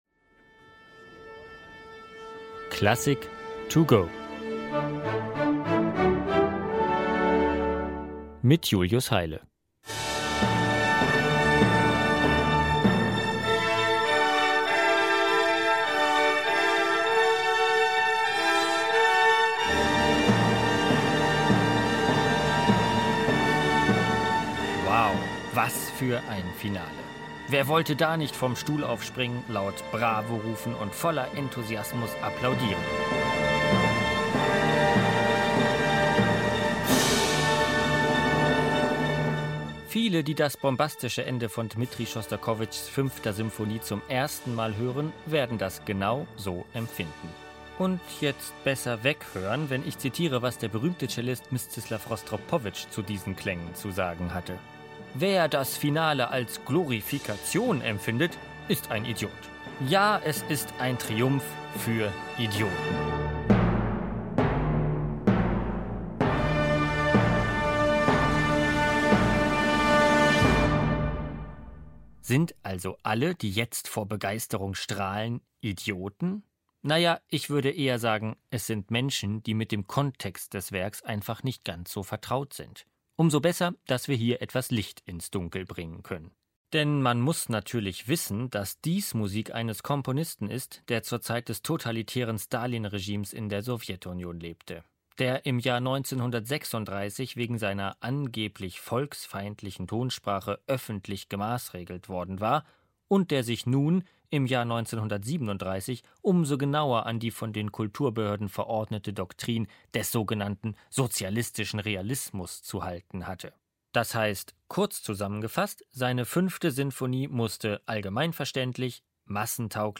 kurzen Werkeinführung.